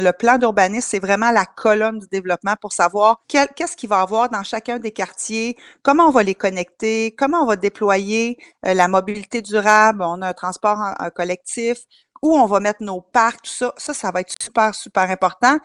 En entrevue, elle a mentionné l’importance de bien mettre à jour ce plan.